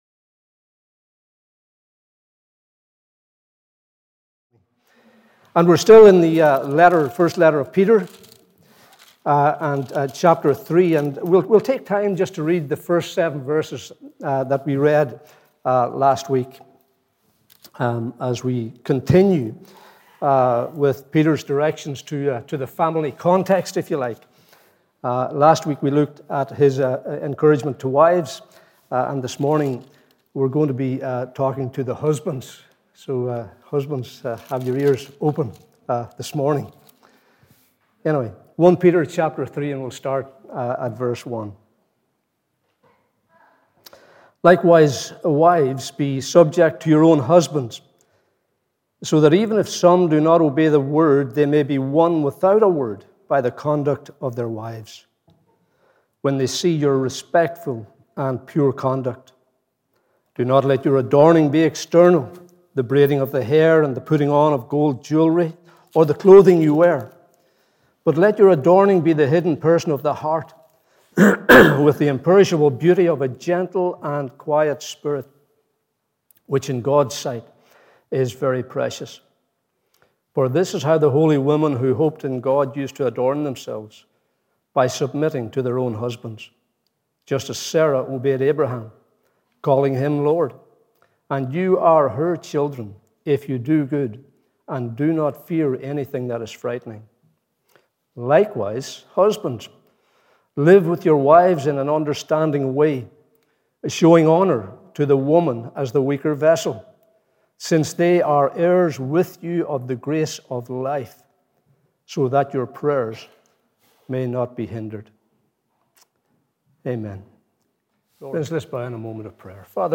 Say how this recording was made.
Morning Service 3rd April 2022